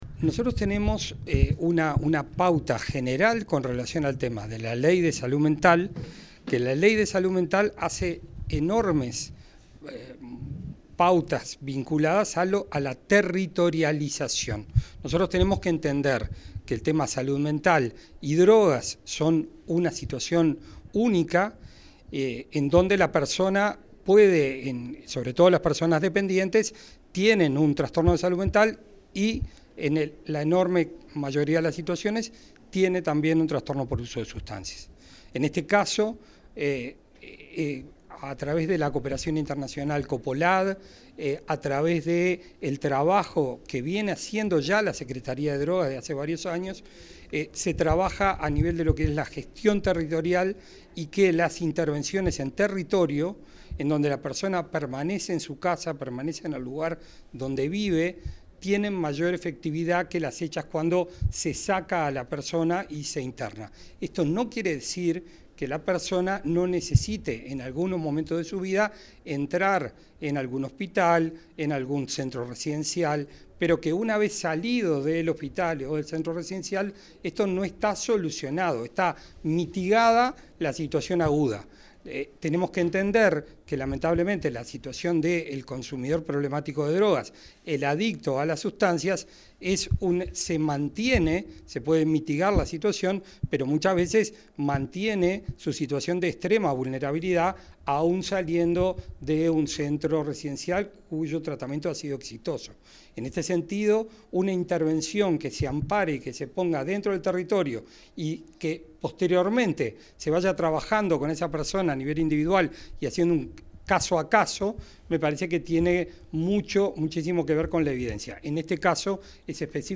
El secretario de la Junta Nacional de Drogas, Gabriel Rossi, efectuó declaraciones durante la firma de un convenio con la Intendencia de Canelones.